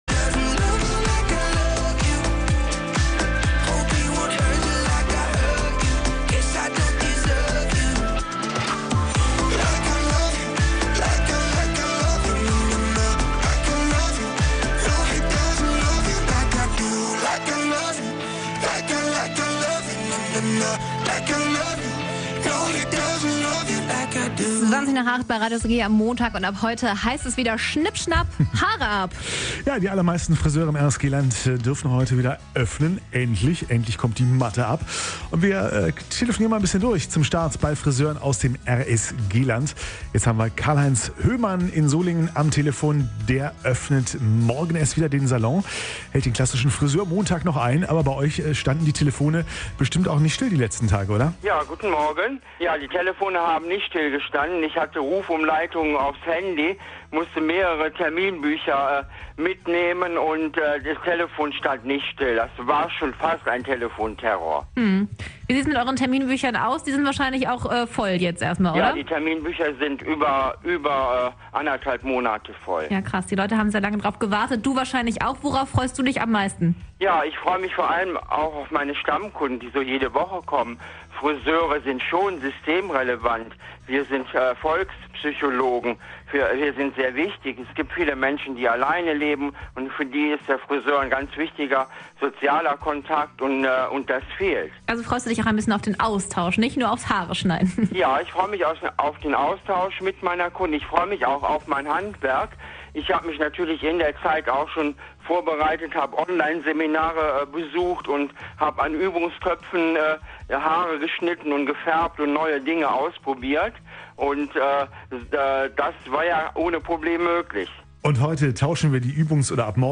haben vorab in der Morgenshow mit ein paar von ihnen telefoniert.